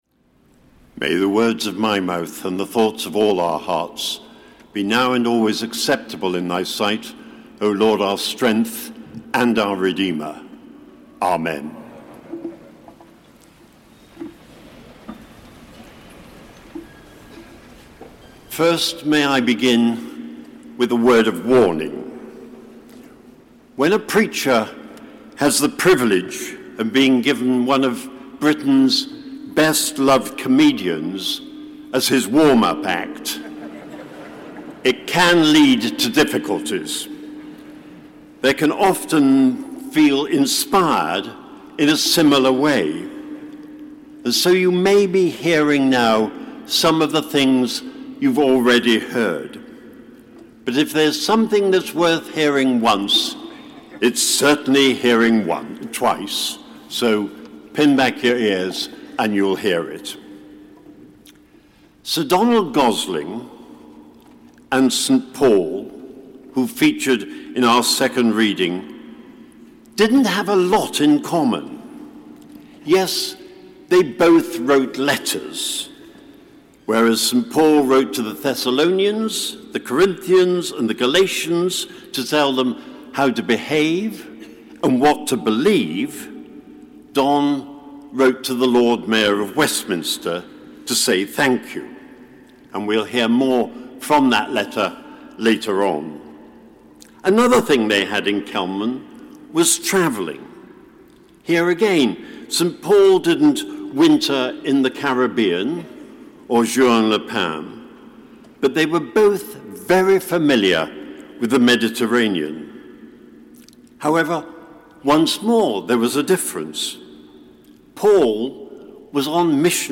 Address given at the Sir Donald Gosling service